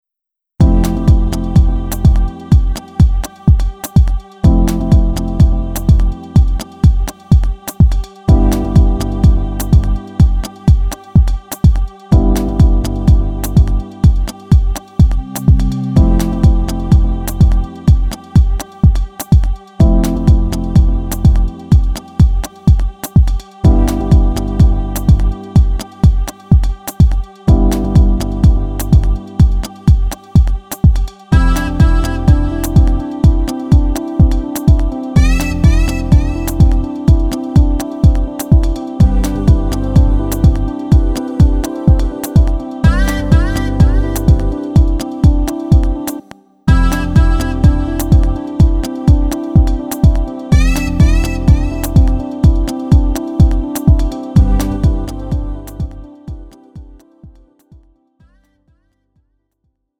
음정 원키 3:29
장르 가요 구분 Lite MR